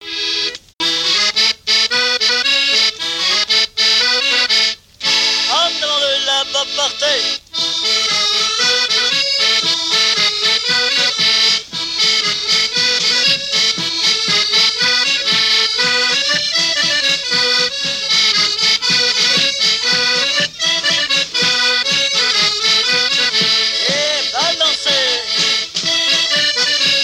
Mémoires et Patrimoines vivants - RaddO est une base de données d'archives iconographiques et sonores.
Chants brefs - A danser
danse : branle : avant-deux
Pièce musicale inédite